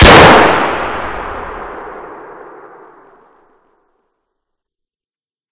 shoot.ogg